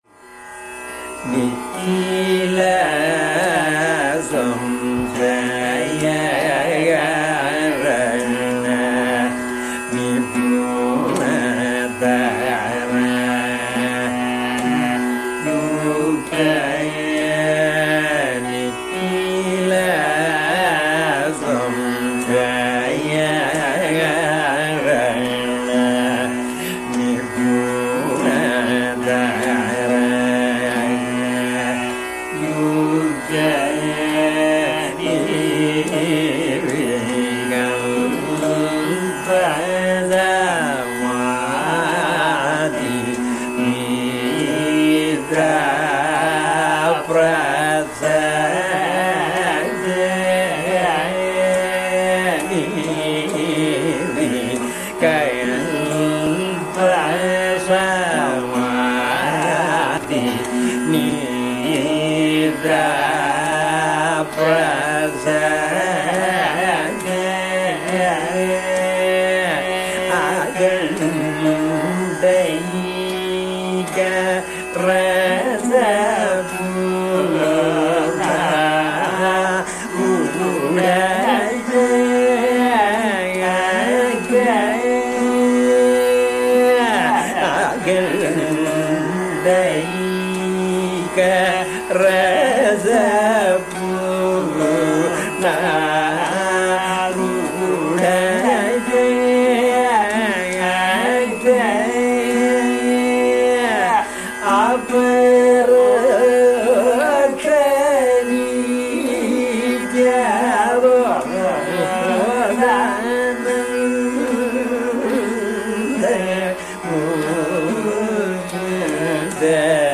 Caranam begins.
Again, each line has its own melodic shape and each (except the last) is repeated with variations.
Caranam melodies often begin in the purvānga and end in the uttarānga, as is the case with this example.
The pallavi occurs once again at the conclusion of the caranam.